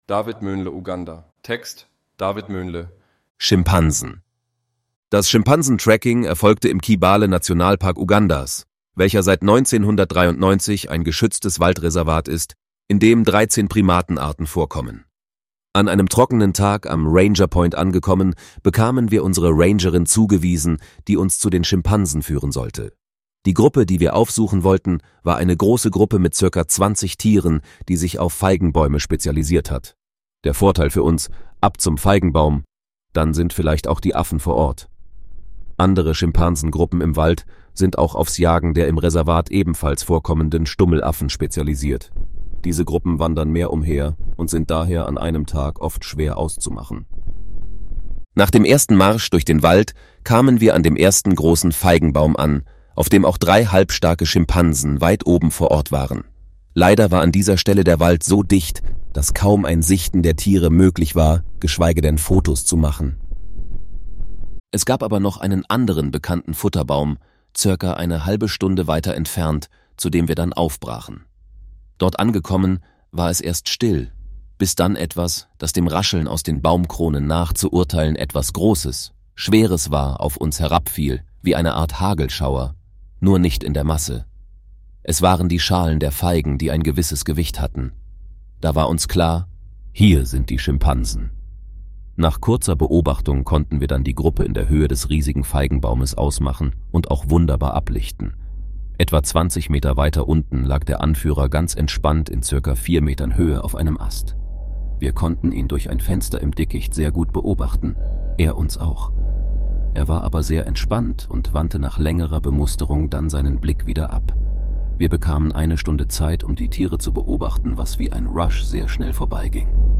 von KI generiert | Wildtierfoto Magazin #2 - Arten-vielfalt